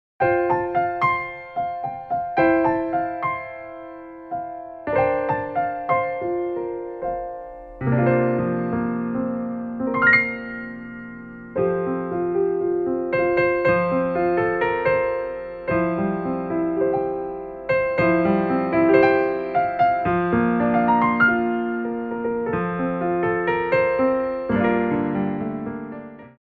4/4 (8x8)